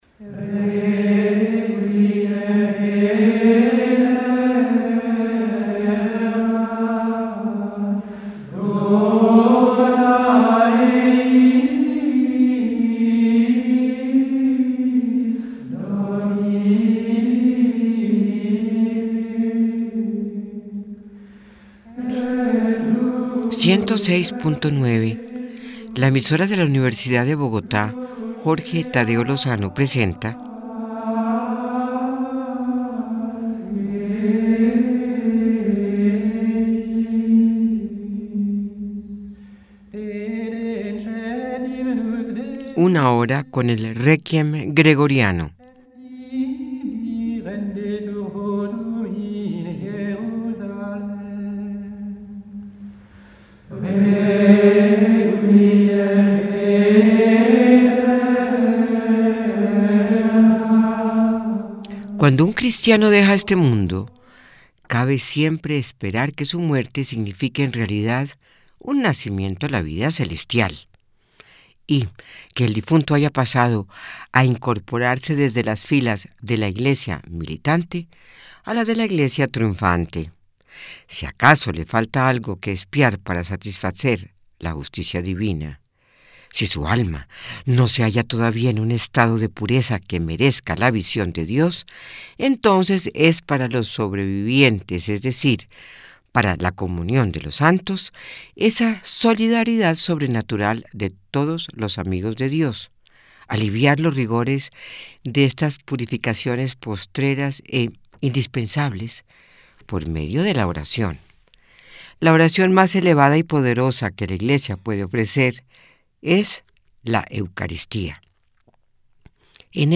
z-una_hora_con_canto_gregoriano.mp3